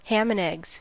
Ääntäminen
IPA: [jɑ]